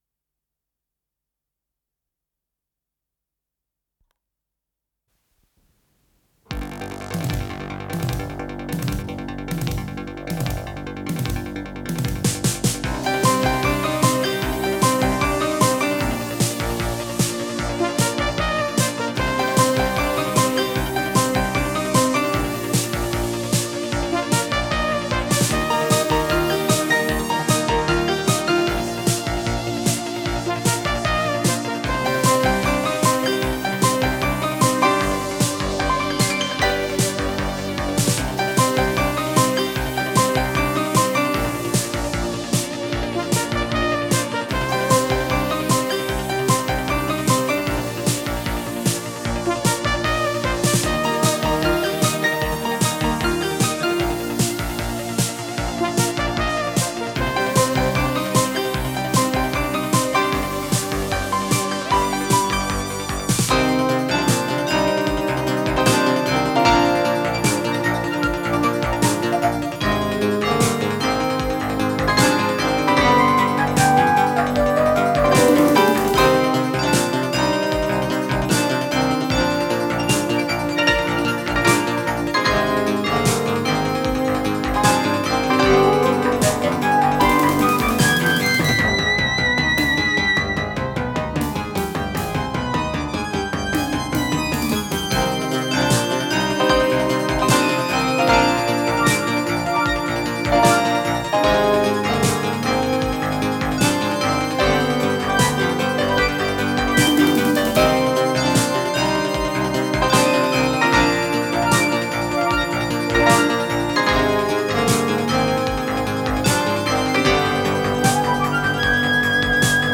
с профессиональной магнитной ленты
синтезатор
ВариантДубль моно